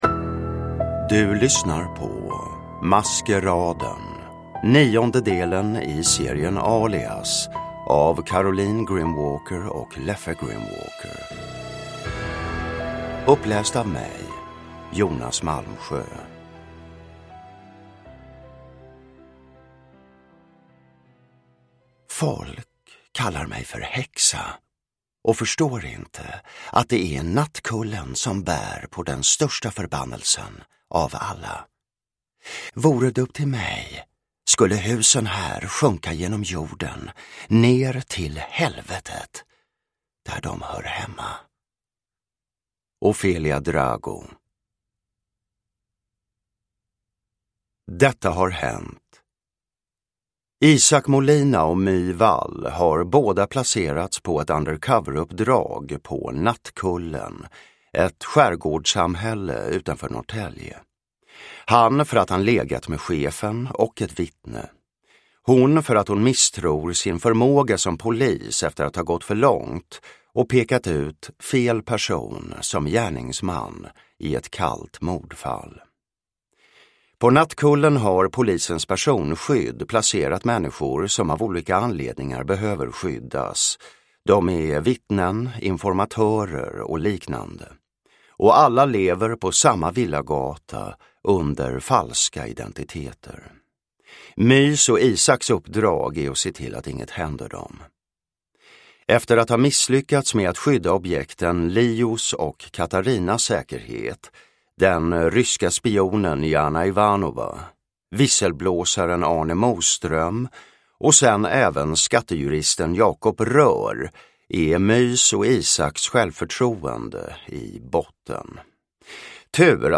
Maskeraden – Ljudbok
Uppläsare: Jonas Malmsjö